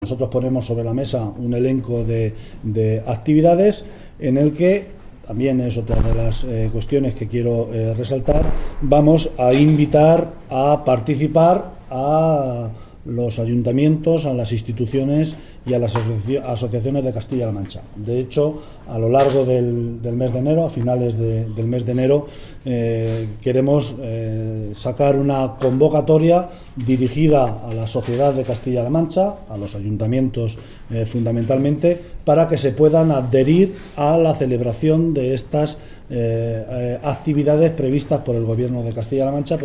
El vicepresidente del Gobierno regional, José Luis Martínez Guijarro, durante la presentación de los actos con motivo del IV Centenario de la muerte de Miguel de Cervantes: